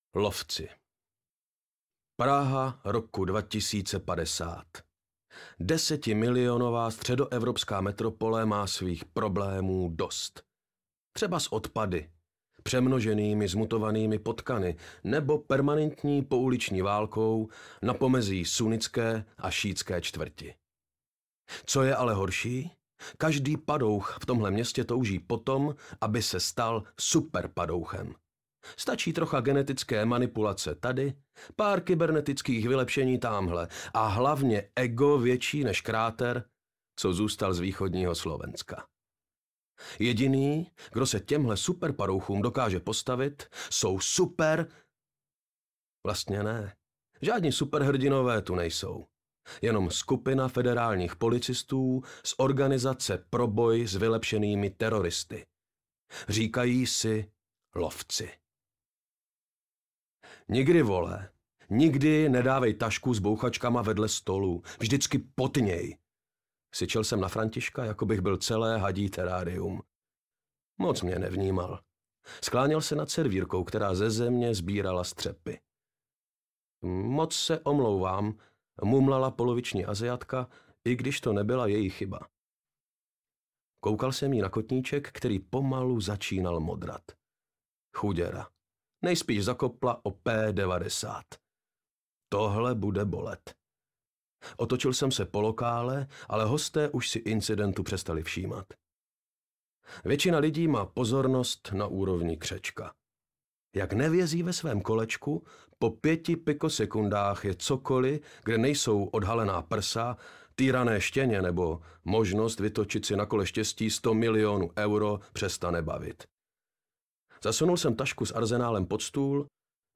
Lovci audiokniha
Ukázka z knihy